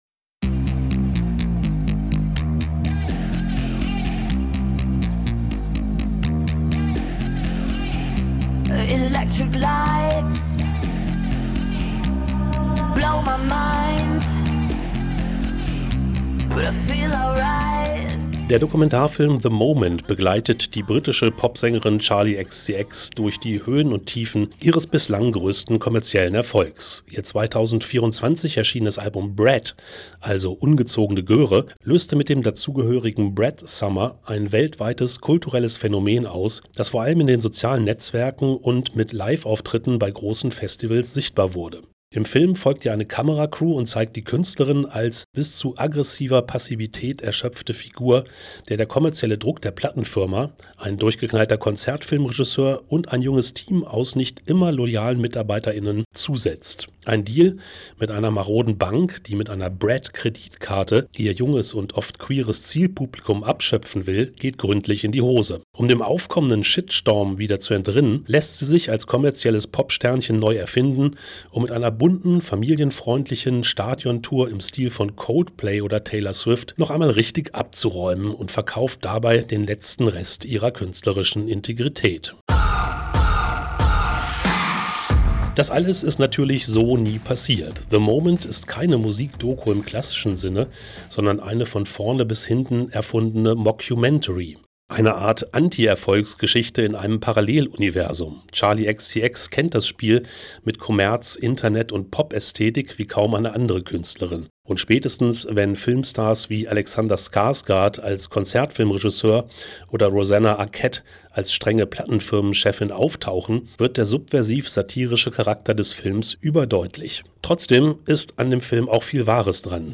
(Dieser Beitrag erschien zuerst als Radiobeitrag in der Sendung „Filmriss – Das Berlinale-Magazin“, einem gemeinsamen Projekt der norddeutschen Bürgersender Kiel FM, Lübeck FM, Westküste FM, Tide Hamburg, Radio Leinewelle und Oldenburg Eins.)